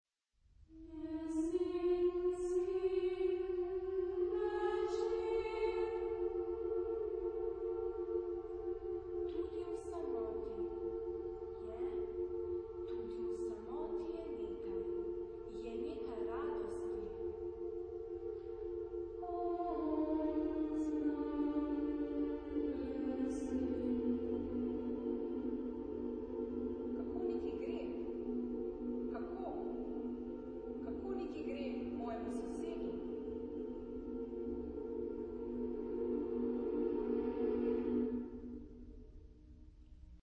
Genre-Style-Forme : Suite ; contemporain ; Profane
Caractère de la pièce : mystique